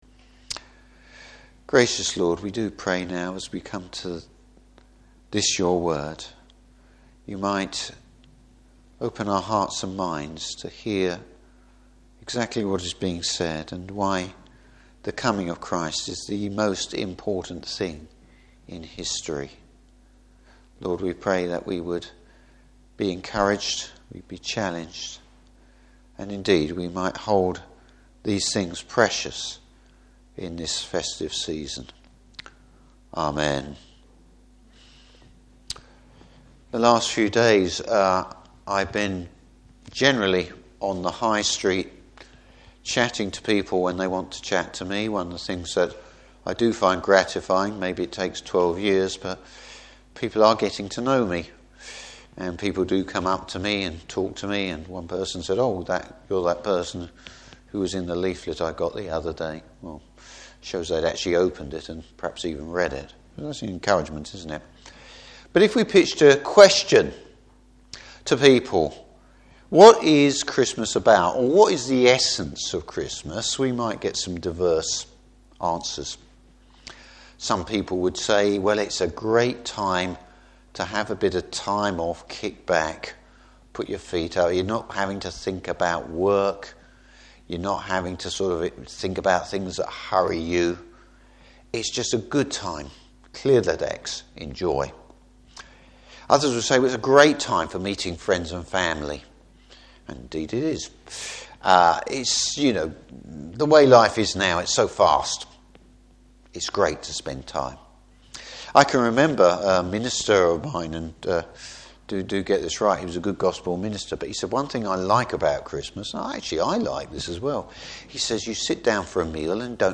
Service Type: Christmas Eve Service.